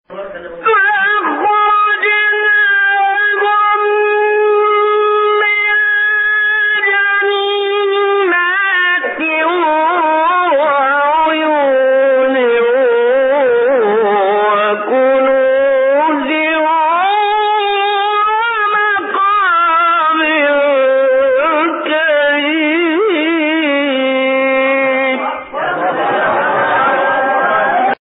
به گزارش خبرگزاری بین المللی قرآن(ایکنا) هشت فراز صوتی از محمد محمود رمضان، قاری برجسته مصری در کانال تلگرامی قاریان مصری منتشر شده است.
این فرازها از تلاوت سوره شعراء است که در مقام‌های بیات، کرد، صبا، حجاز، نهاوند، رست، چهارگاه و سه‌گاه اجرا شده است.
مقام ترکیبی صبا و عجم